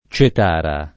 [ © et # ra ]